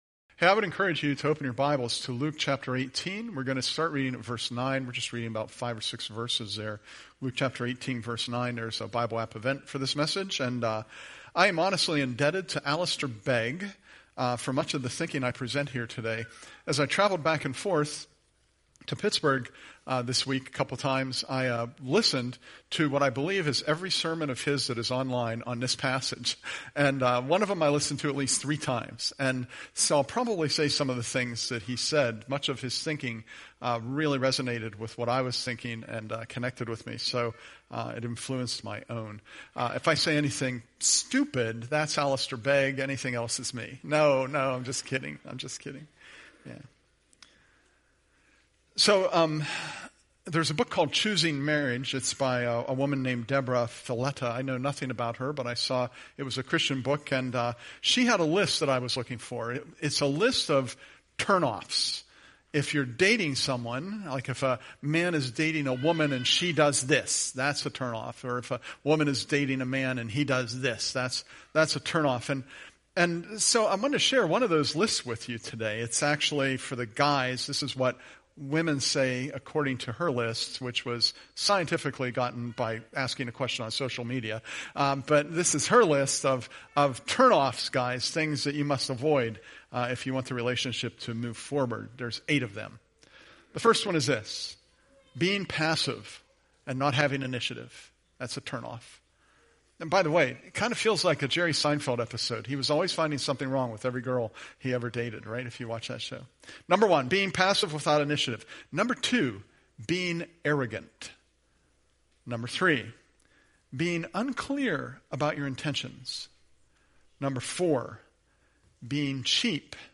Presented at Curwensville Alliance